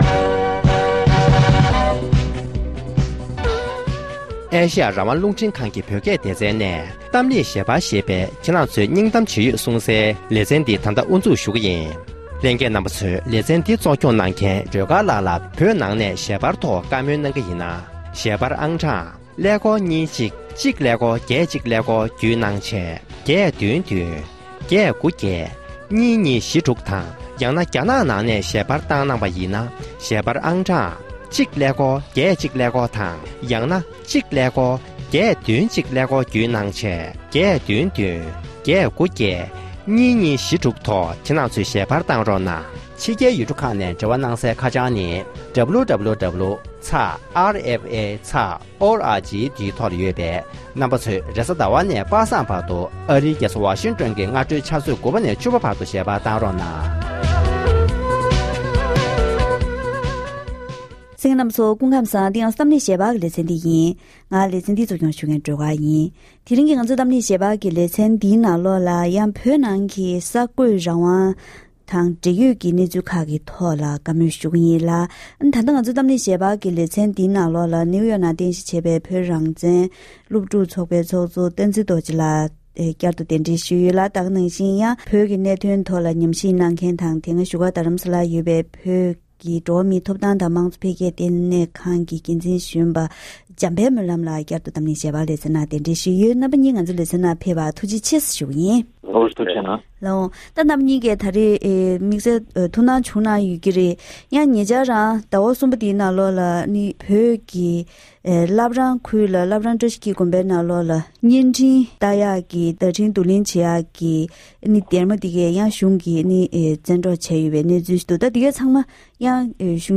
འབྲེལ་ཡོད་མི་སྣར་བཀའ་མོལ་ཞུས་པ་ཞིག་གསན་རོགས་གནང་།།